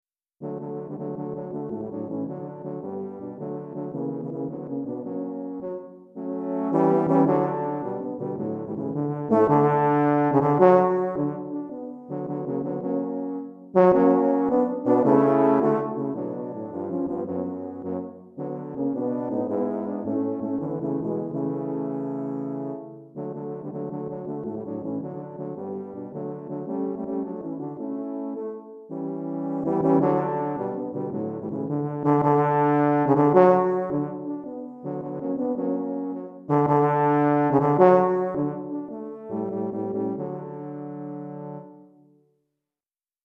3 Tubas